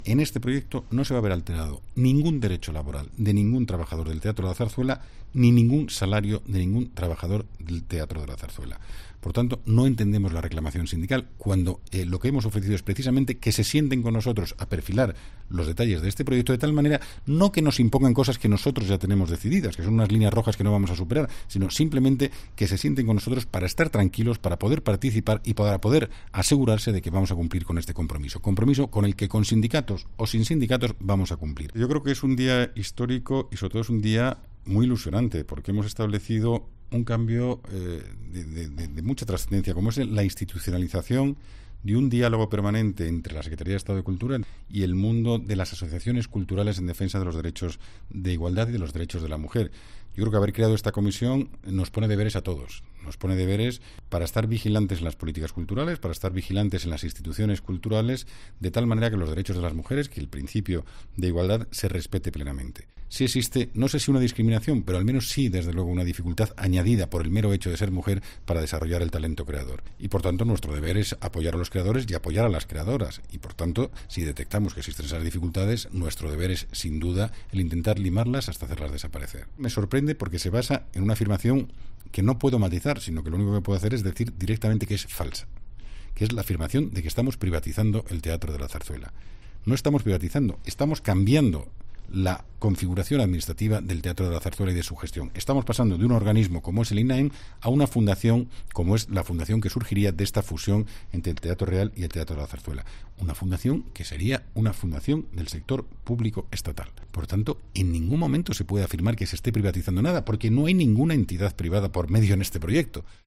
En entrevista con la COPE, el secretario de Estado de CulturaFernando Benzo, ha asegurado que el Gobierno seguirá adelante con la fusión del Teatro Real y del Teatro de la Zarzuela, a pesar de la huelga indefinida convocada por los sindicatos a partir del 24 de marzo.